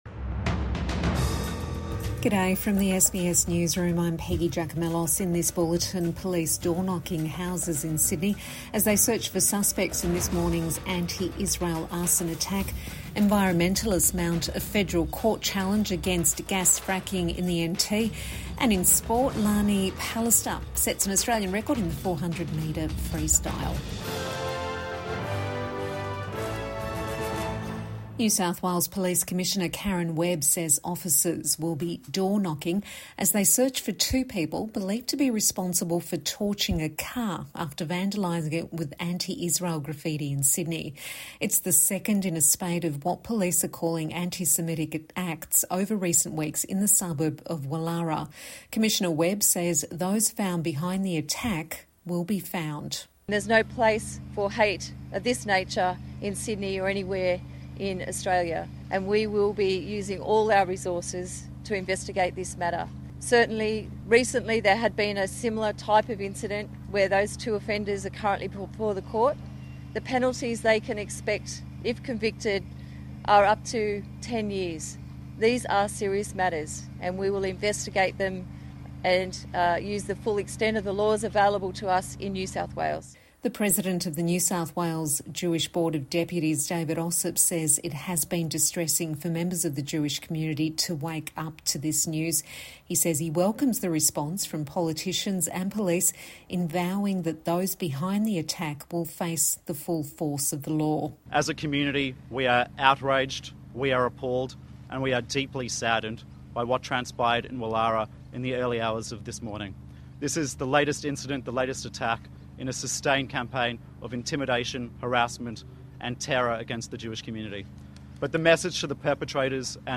Midday News Bulletin 11 December 2024